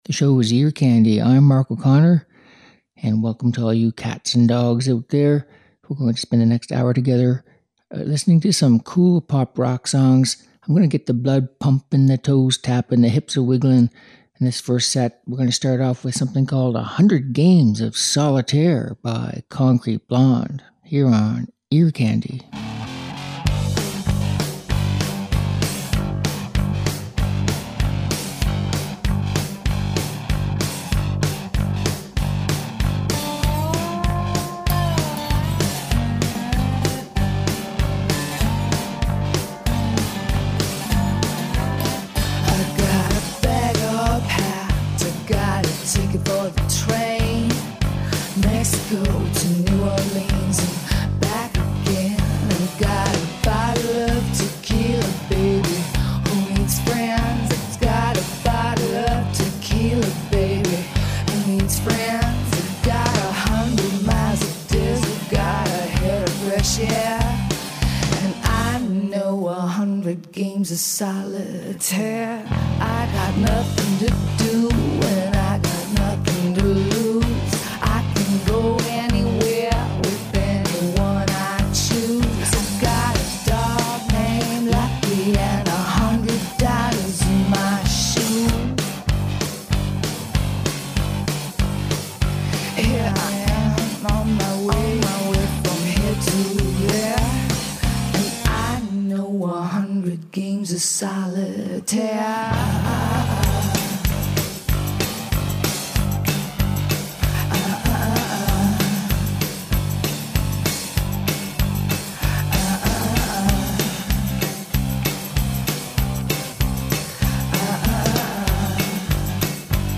Upbeat Pop and Rock Songs